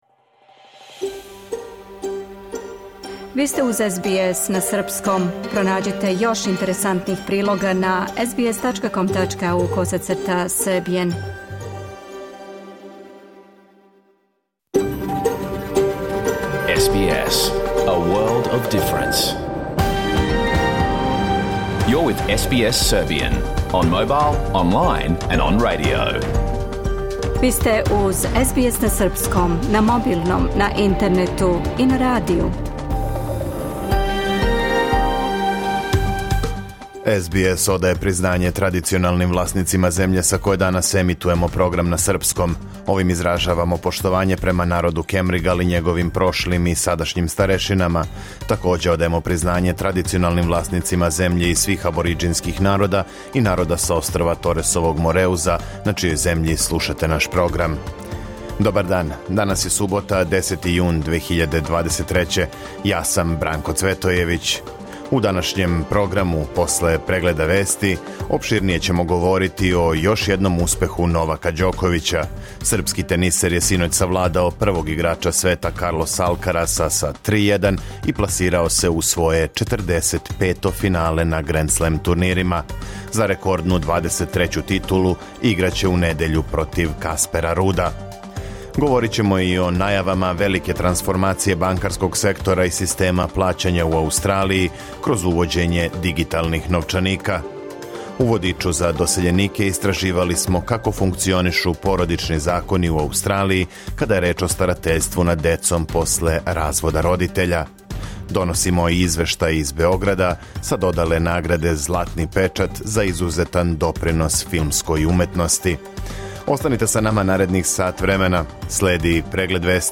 Програм емитован уживо 10. јуна 2023. године
Уколико сте пропустили данашњу емисију, можете је послушати у целини као подкаст, без реклама.